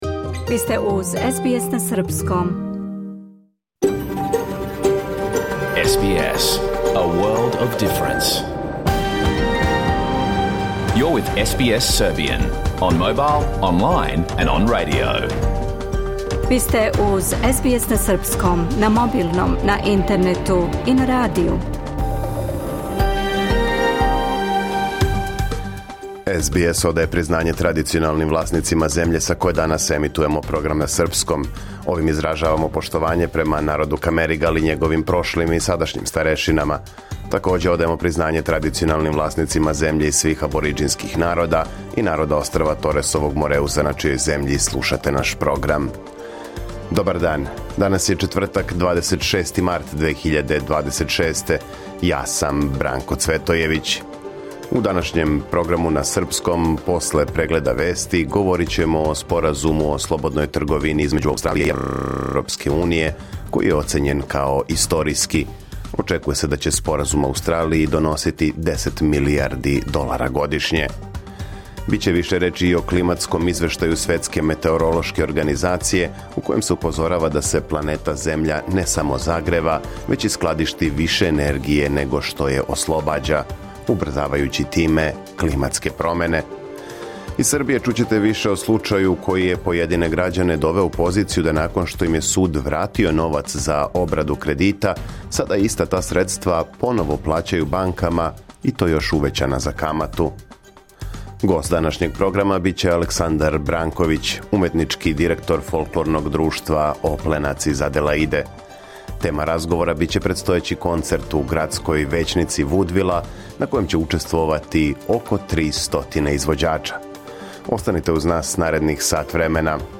Програм емитован уживо 26. марта 2026. године